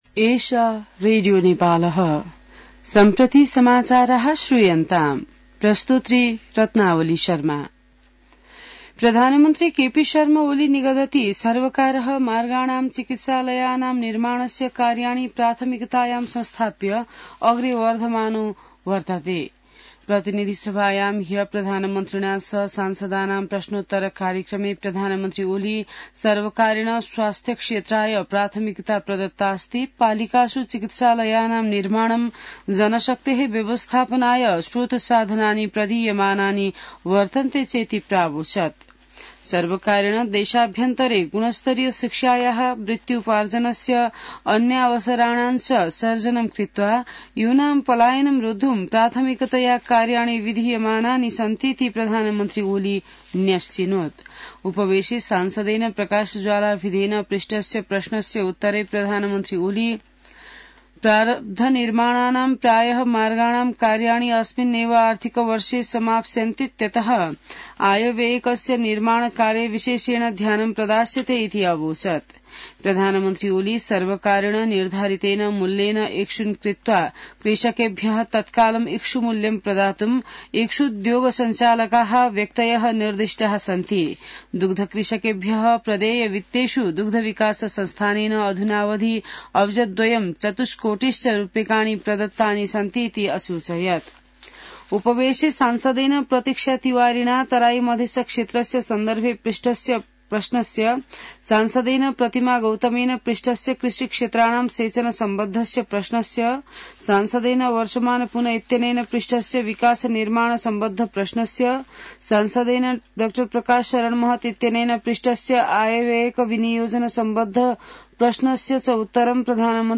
An online outlet of Nepal's national radio broadcaster
संस्कृत समाचार : ६ फागुन , २०८१